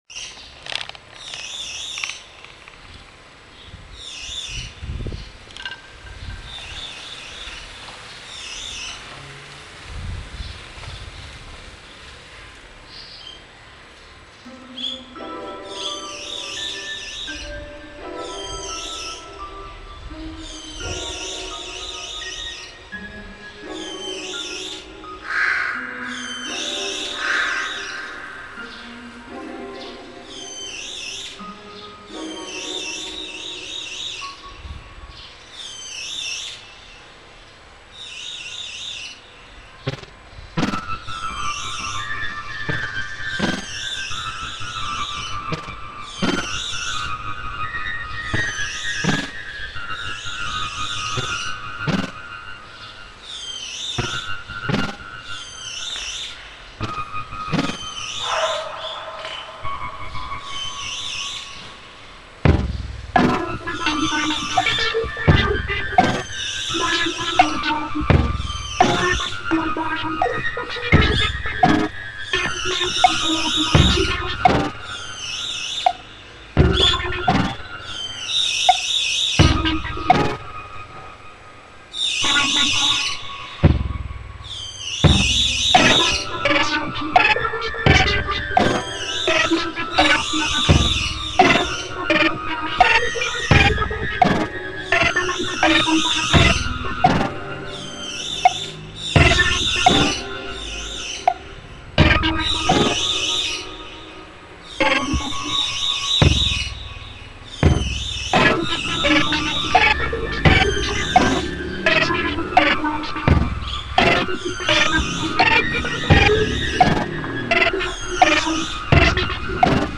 カラスや犬の鳴き声がかわいいです！